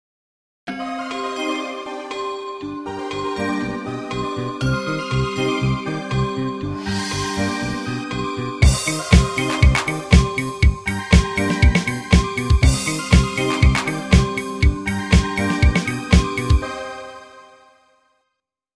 на современном синтезаторе